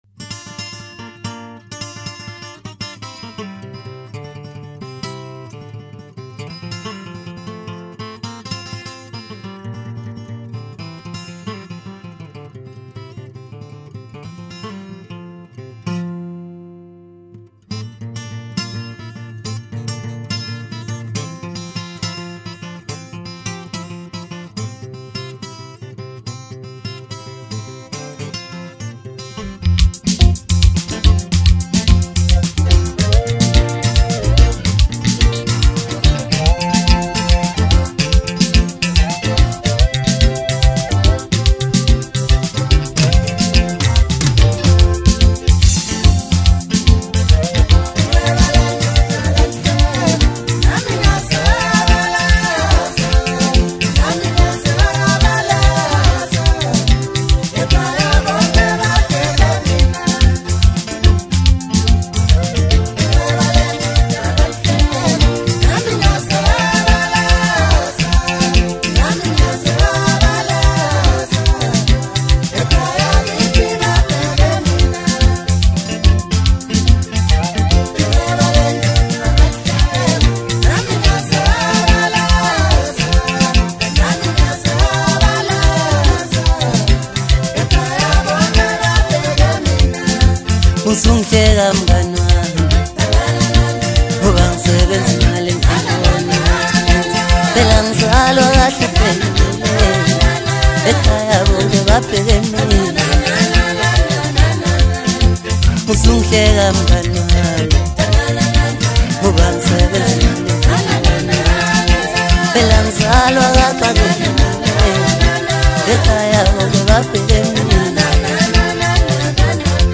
Home » Maskandi » Amapiano
South African singer-songwriter